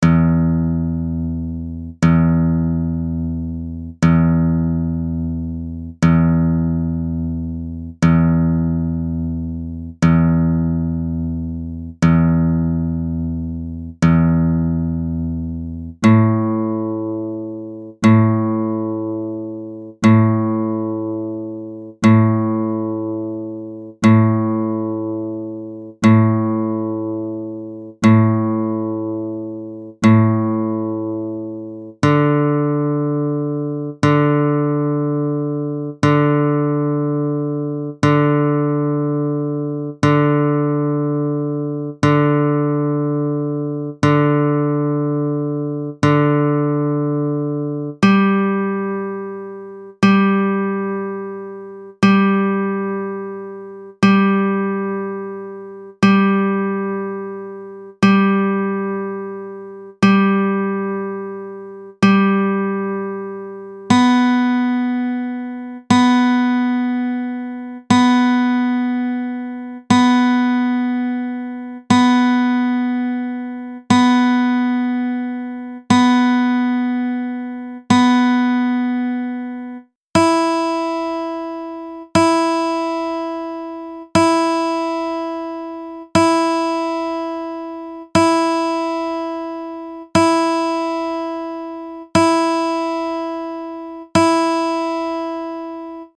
Basics: GUITAR TUNING
The standard right handed guitar tuning is shown in Fig. 1.
Note: In the audio file the guitar is repeatedly picked/plucked rather than trying to tune to a decaying string.
guitartuning.mp3